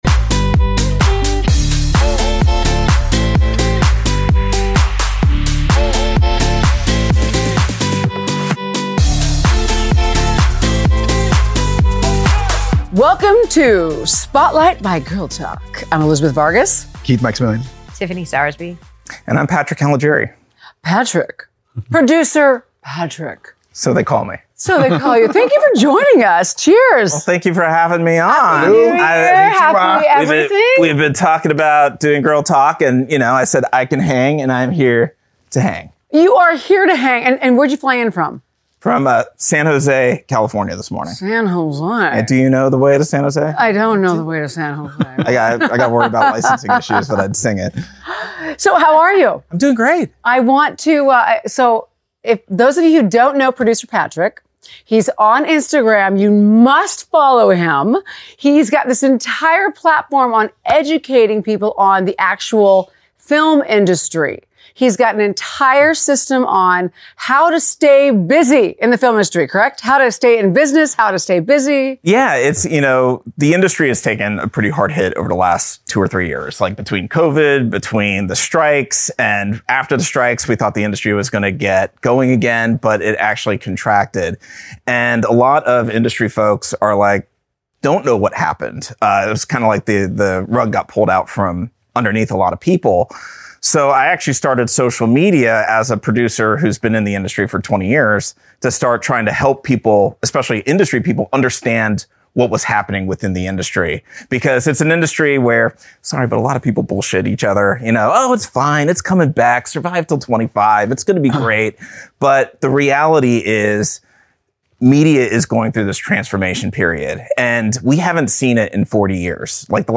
they engage in a compelling conversation